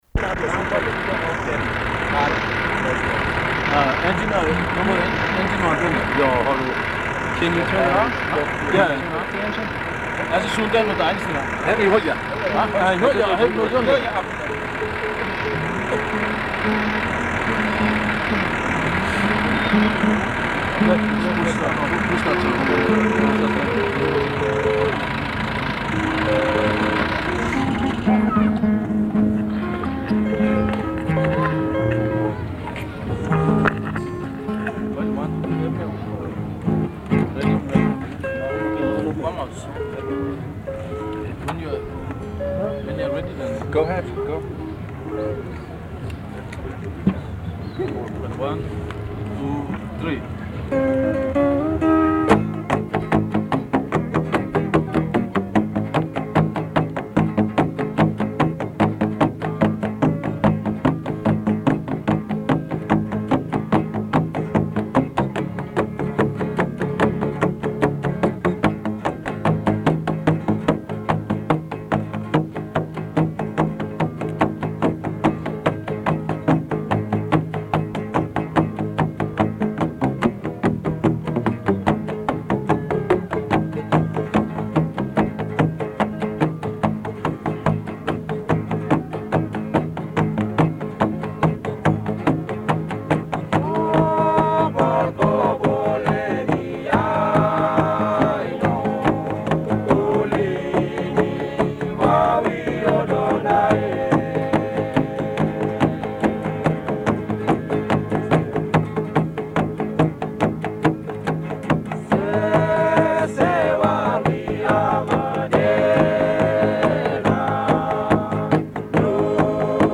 In about August of 1984, I went to the Goroka Highlands Show (Sing-sing was part of the title then I think) .
I captured some of their music on my walkman-like device, including my talking to them in my attempt at Tok Pisin (the pidgin English spoken in New Guinea.)
men playing music on bamboo pipes with thongs (flip-flops) at Goroka Highlands show, Papua New Guinea, 1984 men playing music on bamboo pipes with thongs (flip-flops) at Goroka Highlands show, Papua New Guinea, 1984